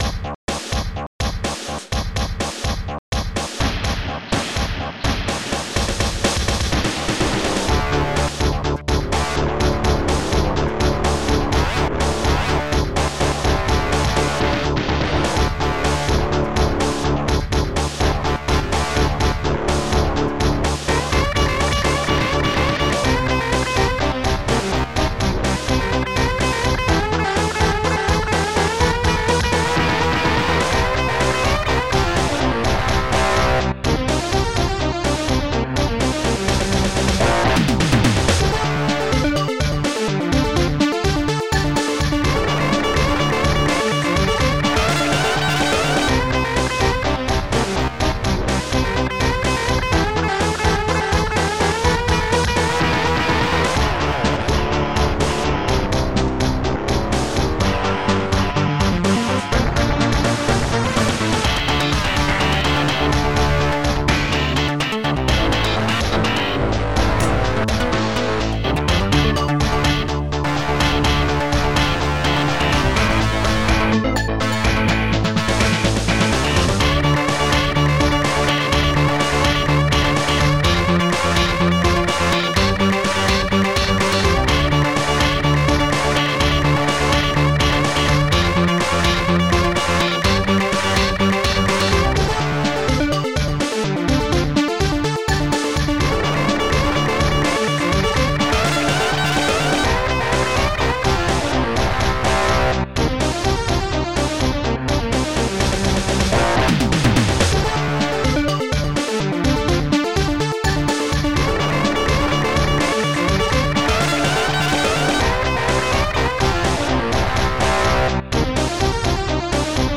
st-06:snare-3
st-04:heavyguitar1
st-04:ledsynth
st-03:Brass5
st-05:Elguitar